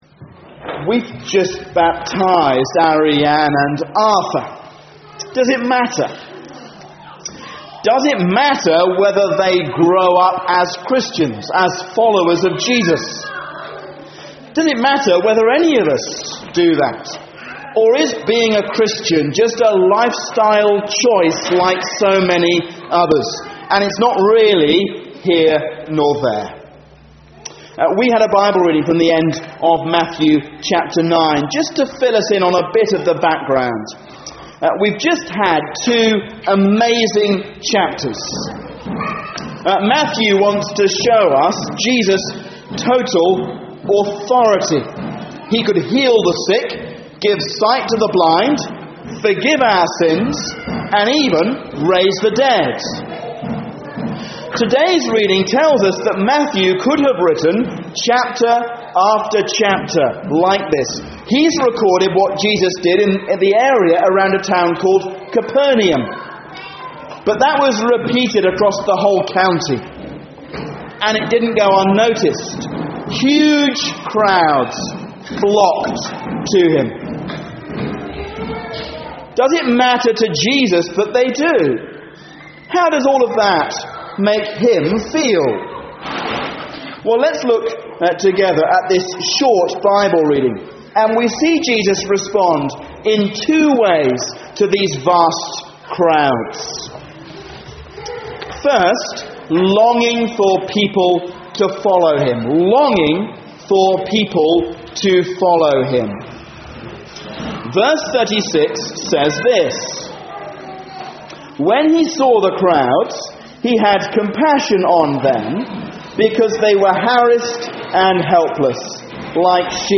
A sermon on Matthew 9:35-38